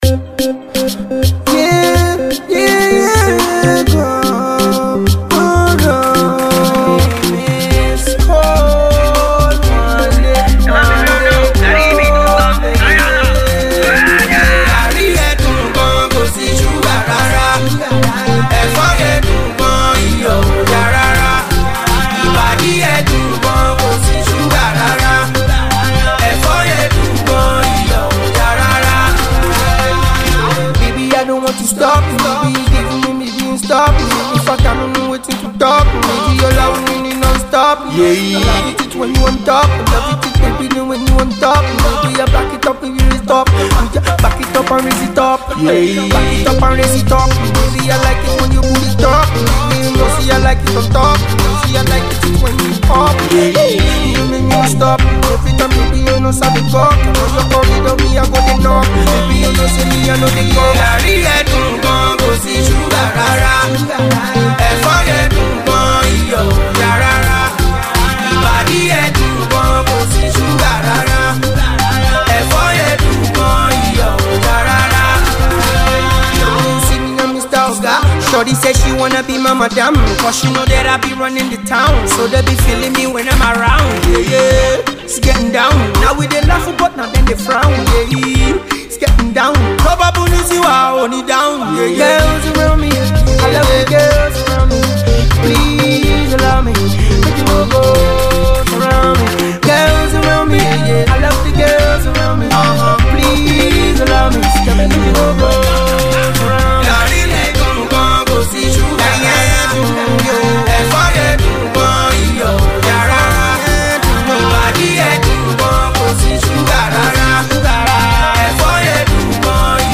unfinished track
catchy freestyle